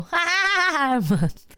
Sound Effects
677 Goofy Ahh Laugh